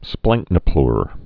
(splăngknə-plr)